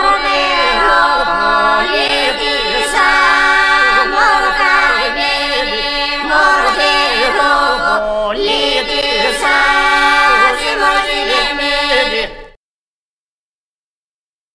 O συνδυασμός αυτός ονομάζεται μονοφωνία β. Στο παραδοσιακό ηπειρώτικο τραγούδι
«Δεροπολίτισα» κάθε φωνή τραγουδάει μια ξεχωριστή μελωδία που συνηχεί και συνδυάζεται με τις υπόλοιπες. Ο συνδυασμός αυτός ονομάζεται πολυφωνία γ. Tο τραγούδι